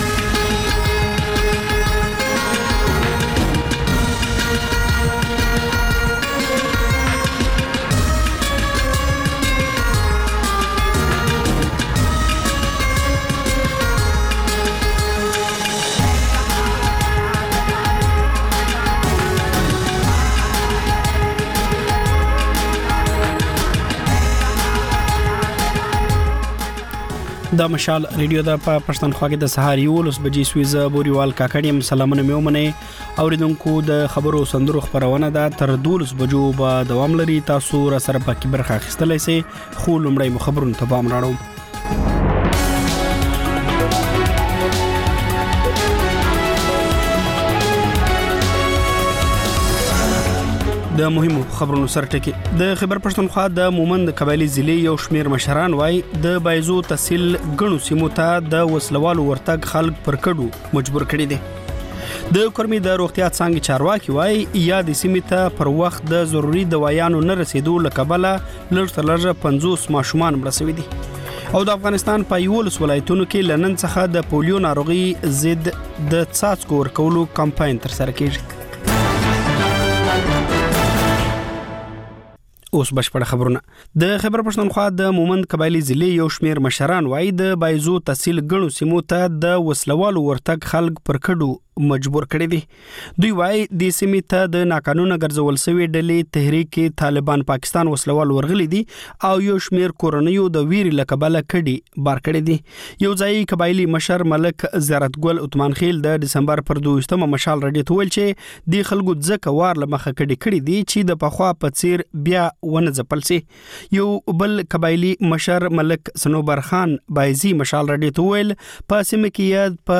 په دې خپرونه کې تر خبرونو وروسته له اورېدونکو سره په ژوندۍ بڼه خبرې کېږي، د هغوی پیغامونه خپرېږي او د هغوی د سندرو فرمایشونه پوره کول کېږي.